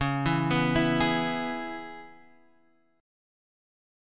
Dbm7 chord